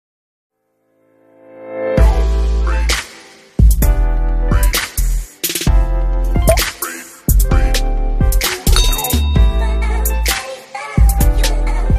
Cute Intro For Youtube.